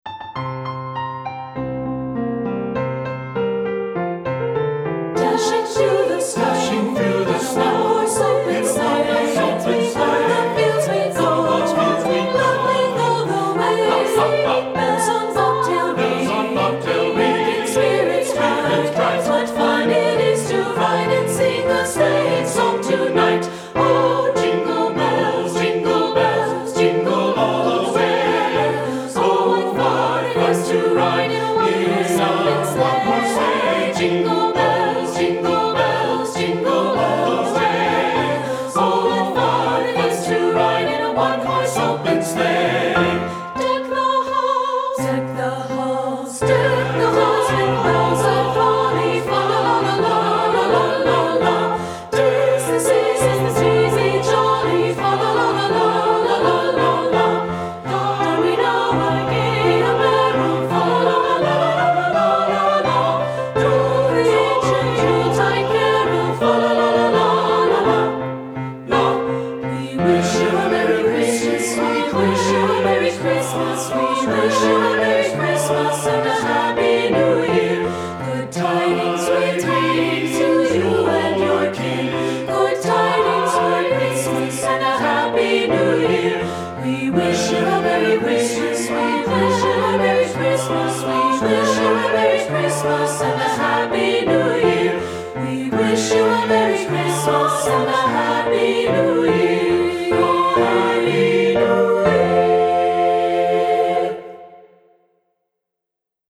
Voicing: 2 or 3-Part Mixed